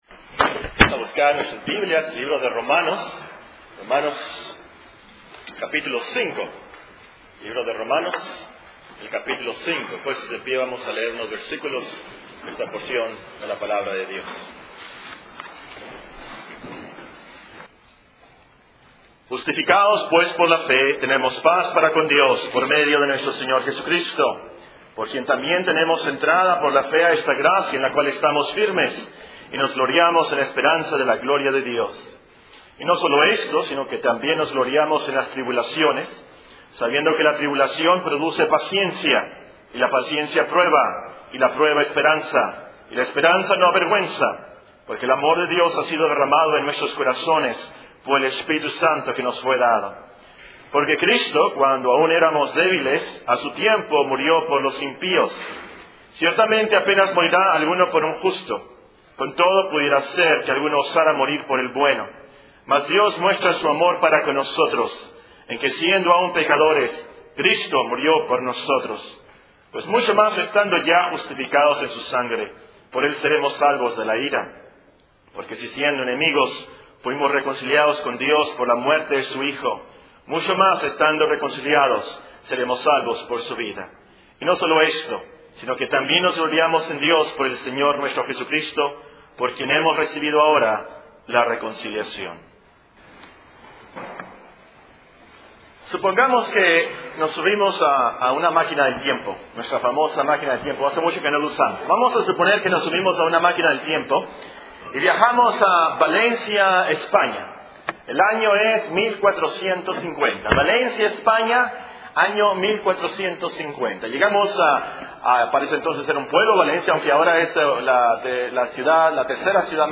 Sermones Biblicos Reformados en Audio, porque la Fe viene por el Oir